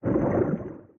cp-anim3-bubbles-rise.ogg